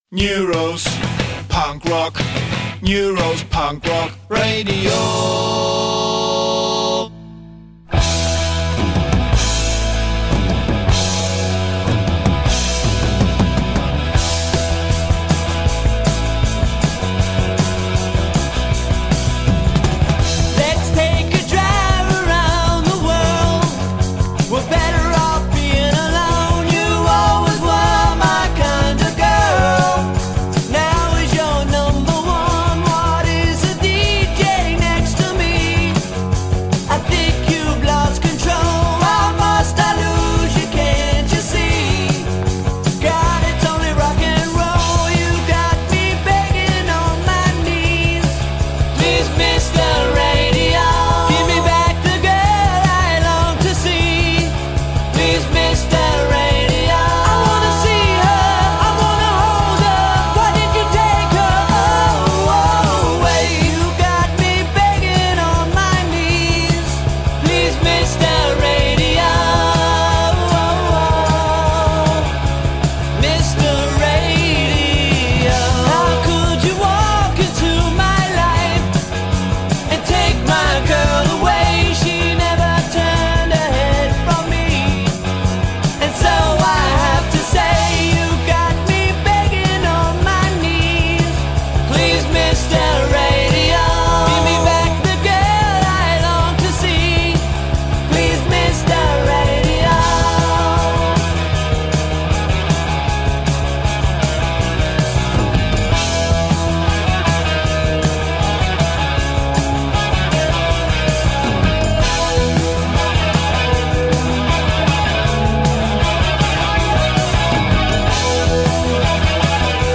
Da ich aber andererseits dann auch Ehrgeiz habe, wurde der Kack nun eben nochmal aufgenommen. Zum Glück konnte ich noch den Anfang retten – also wundert euch nicht über die Oster-Ansage zu Beginn…